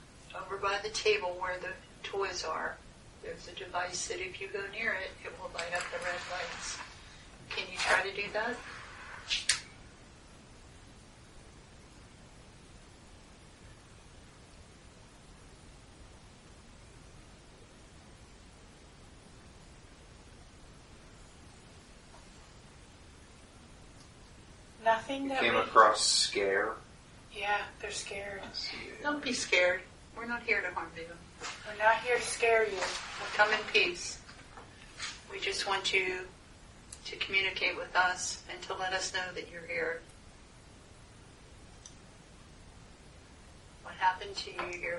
Recording 1 – Open Area:
03-Basment_01.mp3